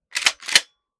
12ga Pump Shotgun - Pump 001.wav